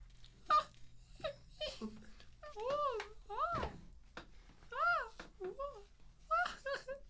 描述：一个女孩在电话上交谈。声音已被清理并压缩，使声音更亮。
标签： 通话 语音 西班牙 女性 谈话 声乐 电话 女人
声道立体声